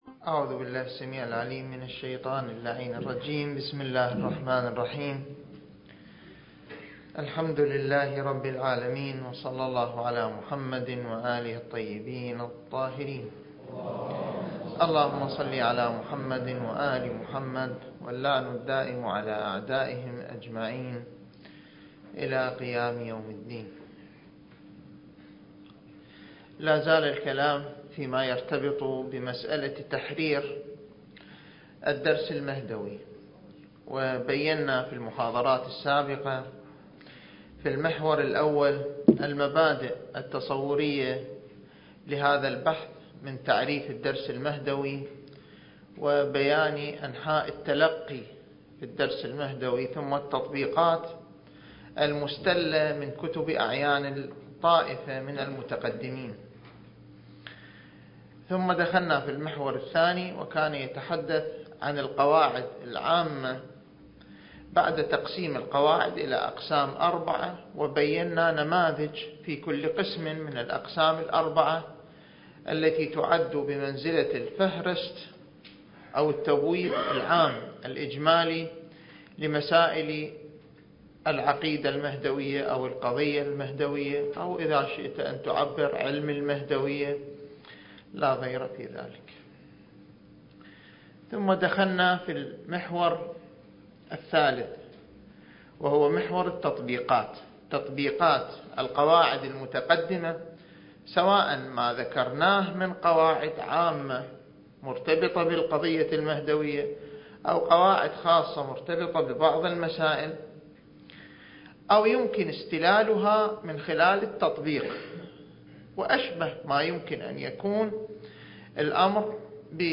الدورة المهدوية الأولى المكثفة (المحاضرة الثلاثون)
المكان: النجف الأشرف